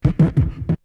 Beatbox 5.wav